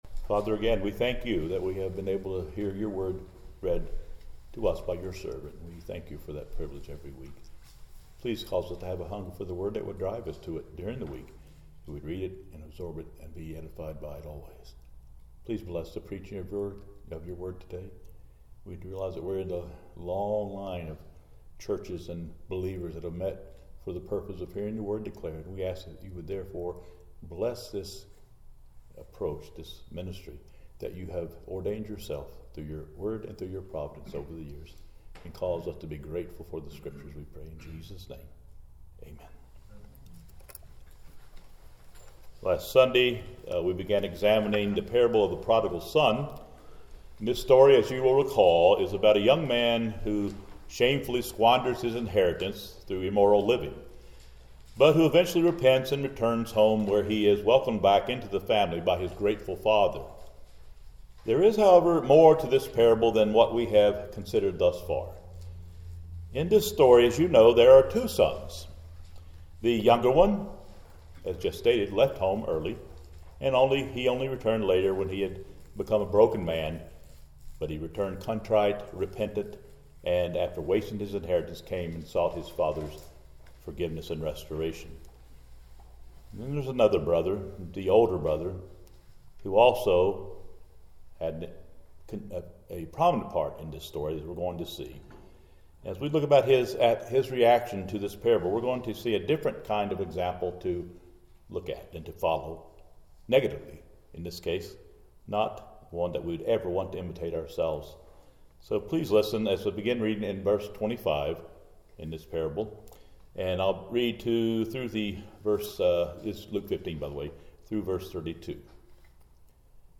Luke 15:11–32 Service Type: Sunday Service Topics